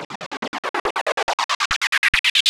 pbs - fki [ Transition].wav